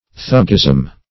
Thuggism \Thug"gism\, n.